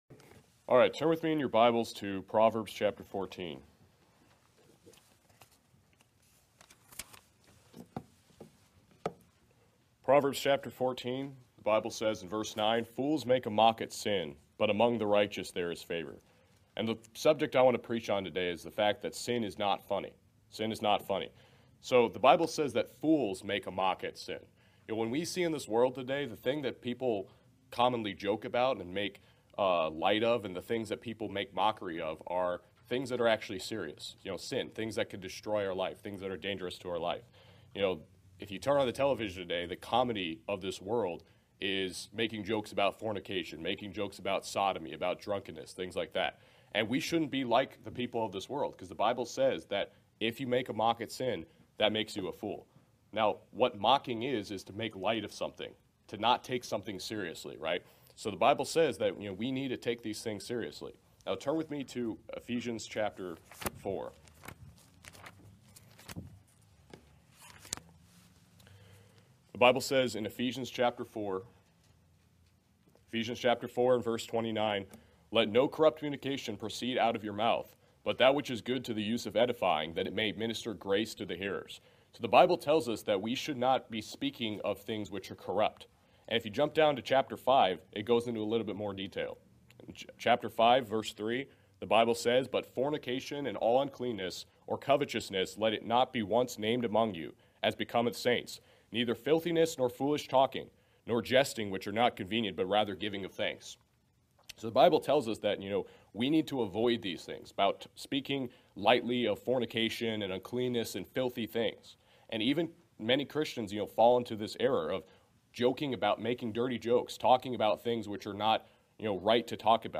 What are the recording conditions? FWBC Preaching Class 2022